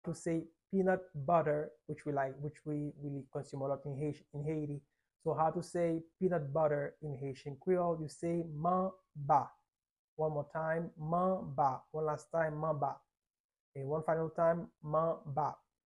How to say “Peanut Butter” in Haitian Creole - “Manba” pronunciation by a native Haitian Teacher
“Manba” Pronunciation in Haitian Creole by a native Haitian can be heard in the audio here or in the video below: